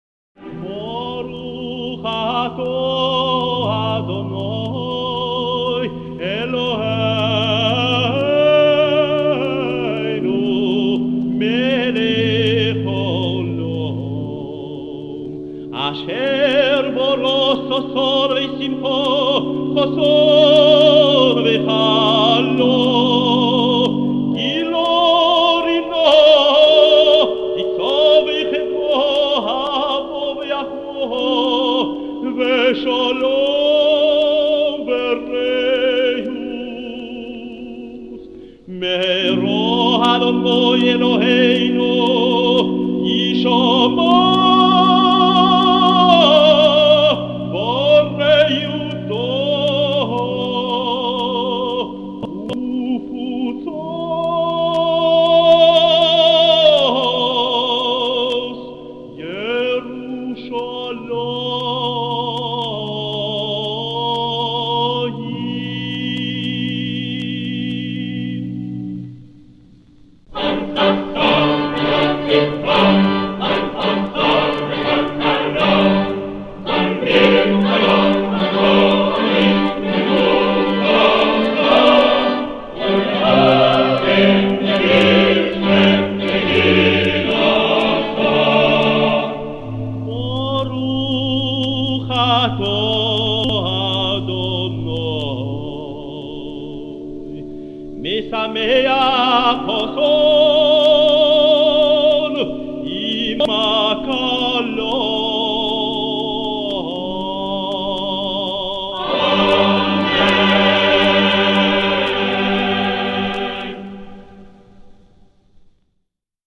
7e bénédiction (rite ashkenaze
accompagné à l’orgue